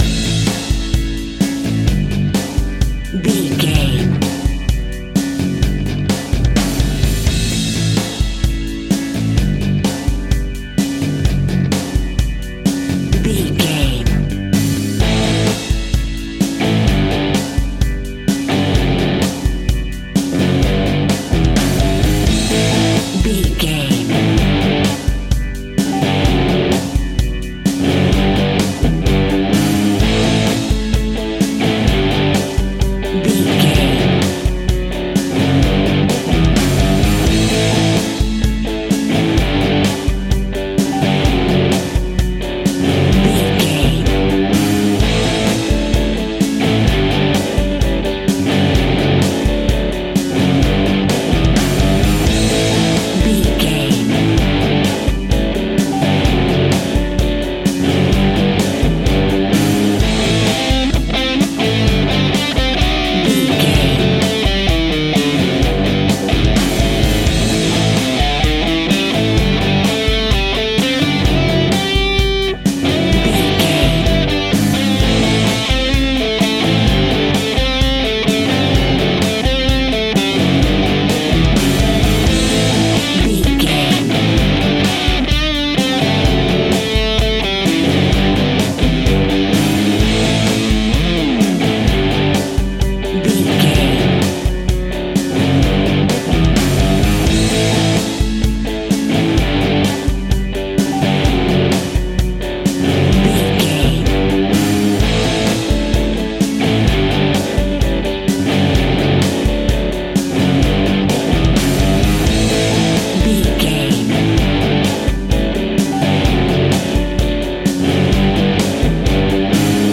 Ionian/Major
indie pop
indie rock
pop rock
sunshine pop music
drums
bass guitar
electric guitar
piano
hammond organ